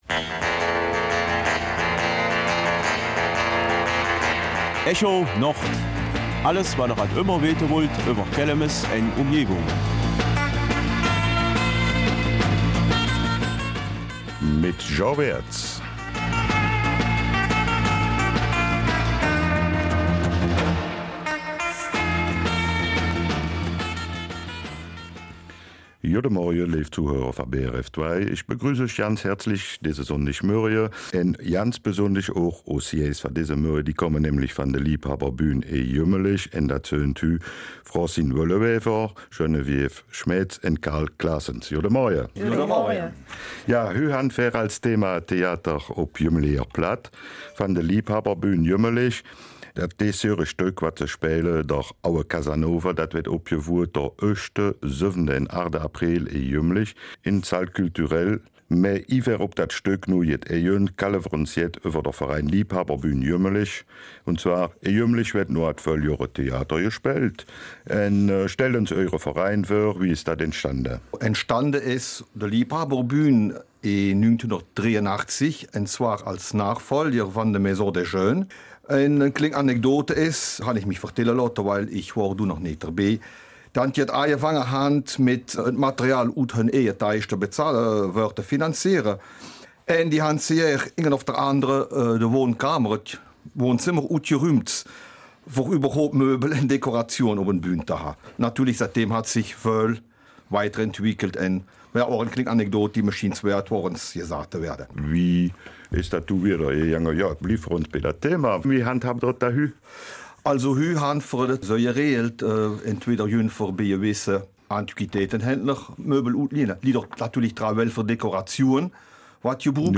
Wie die Vereinigung entstanden ist, welche Ziele sie verfolgt und was es mit der Kindergruppe auf sich hat, verraten uns die Studiogäste